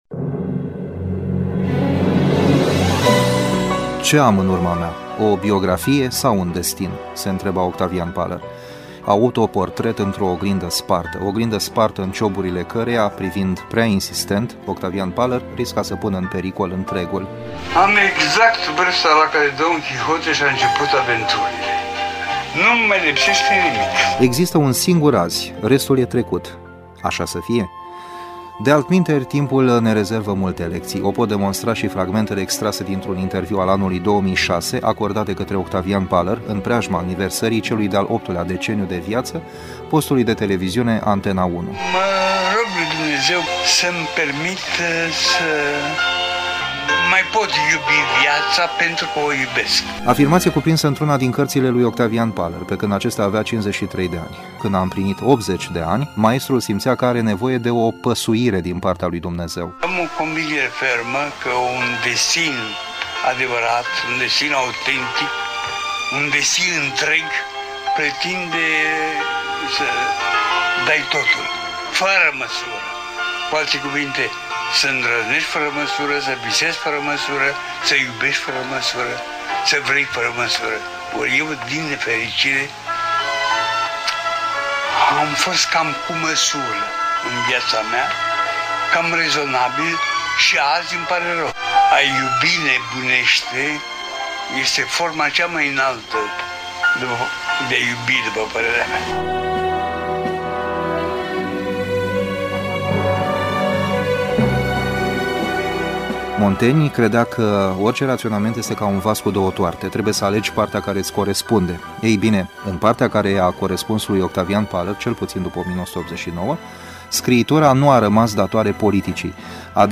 I-am citit întreaga operă, par îndreptățit să vă propun următorul Audio-Feature: